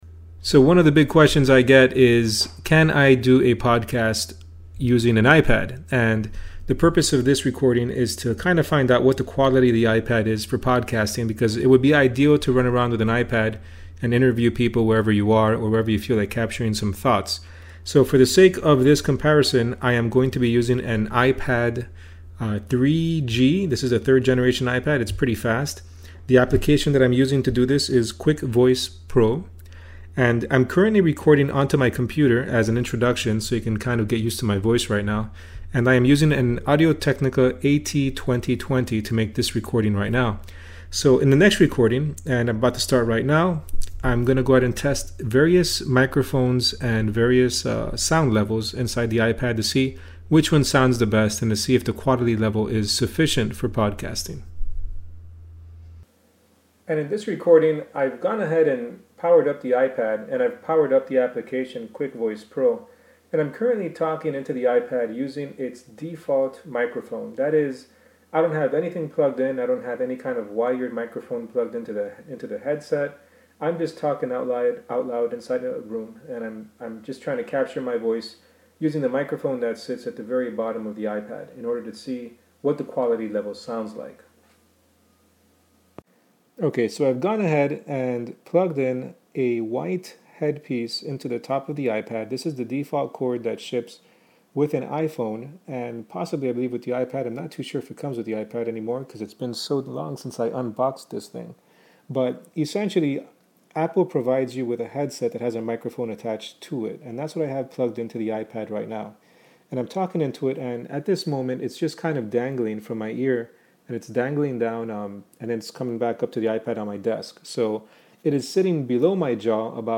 In particular, I wanted to find out what sounds better, the iPad onboard microphone, the iPad white wired headset or an Audiotechnica AT2020USB plugged in using the USB to iPad Camera Connection converter.
Raw Podcast Recording with iPad – Various Microphones
(No normalization or effects applied)
The onboard microphone was the worst of them all.
The wired microphone showed a significant improvement.
The sound quality of the AT2020 was great…but the levels were very, very low.
Recording-Podcast-in-iPad-raw.mp3